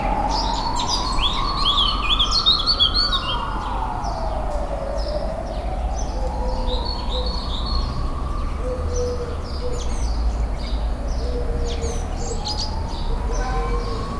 Original creative-commons licensed sounds for DJ's and music producers, recorded with high quality studio microphones.
birds singing in garden 3.wav
birds_singing_in_garden_9_Ffb_RL5.wav